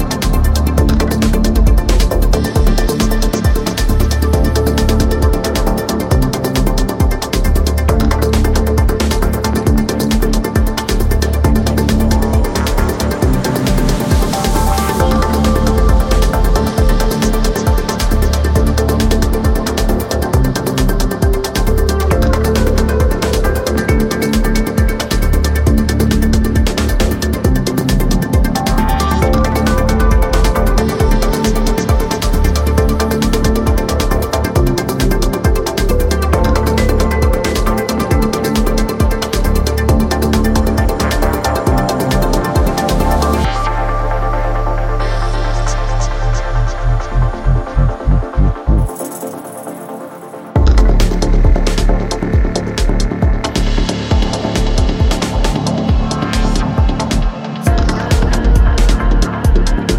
ダブテックを軸により間口の広いスタイルのテクノを表現した充実作です。